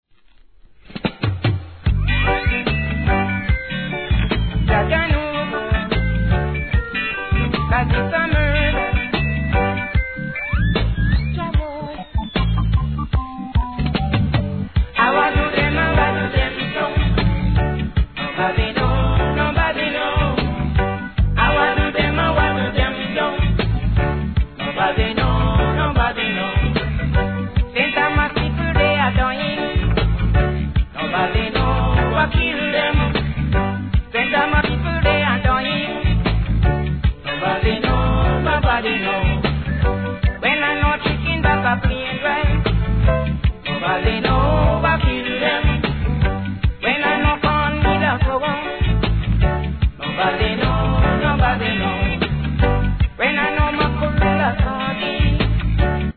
REGGAE
後半はDUBです。